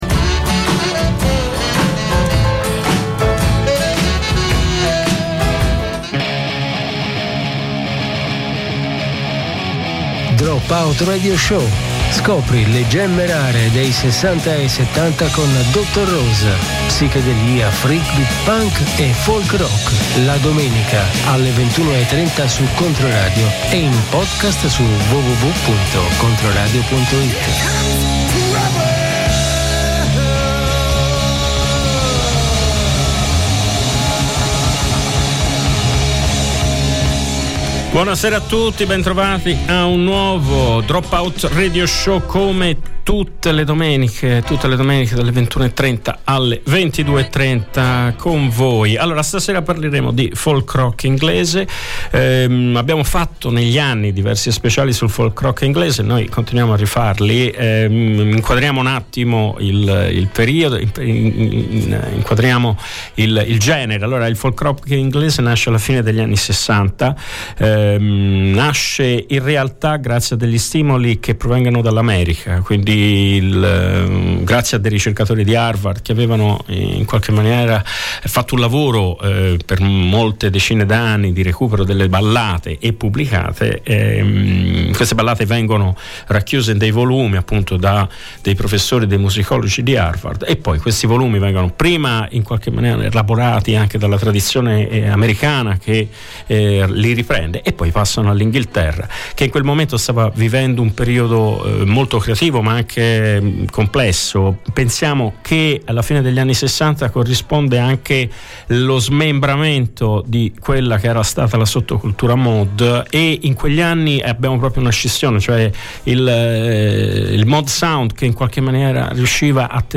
Scopri le gemme rare degli anni '60 e '70: psichedelia, freakbeat, punk e folk rock.